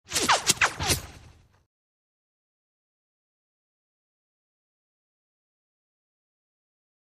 Laser Zaps | Sneak On The Lot
Laser Blasts; Whiz By, Fast And Close.